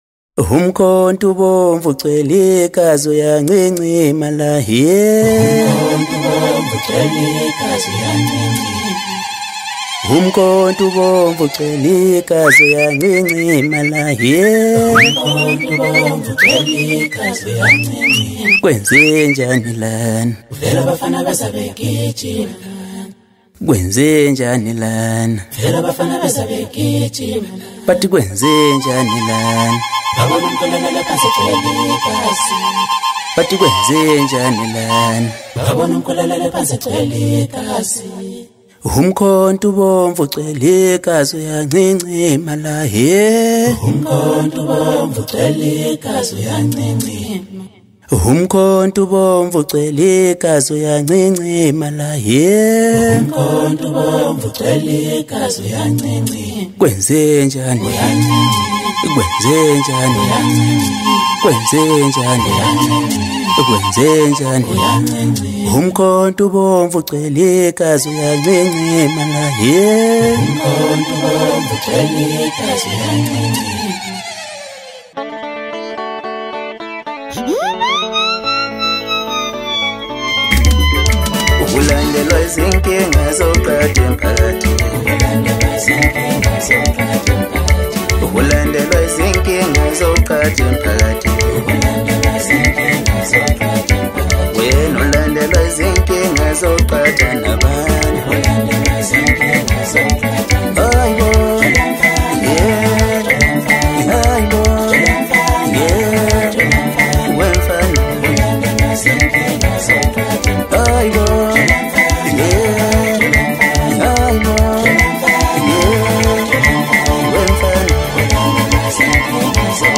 Home » Album?EP » DJ Mix » Hip Hop » Maskandi